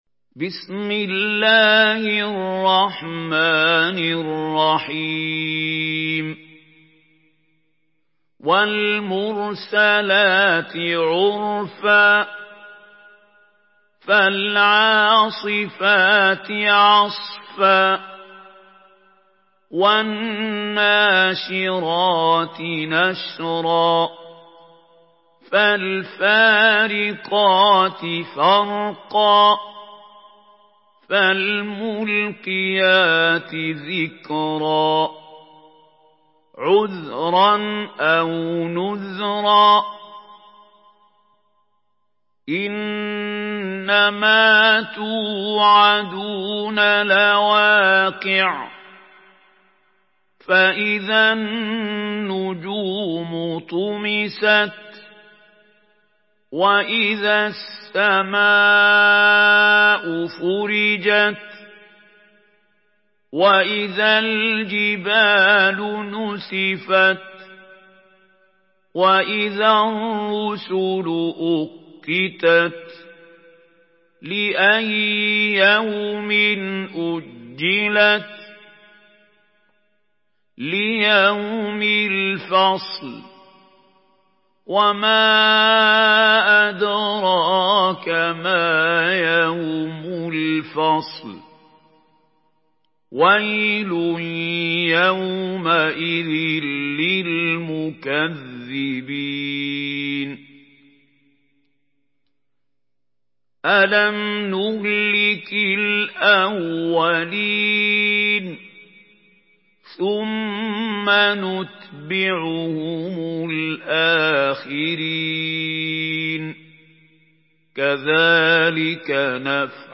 Surah Al-Mursalat MP3 by Mahmoud Khalil Al-Hussary in Hafs An Asim narration.
Murattal Hafs An Asim